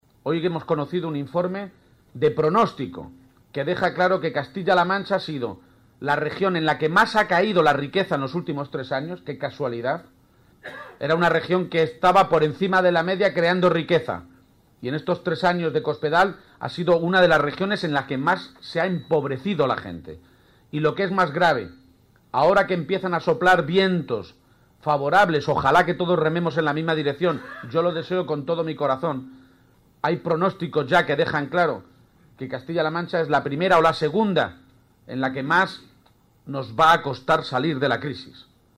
Audio Page en La Solana 3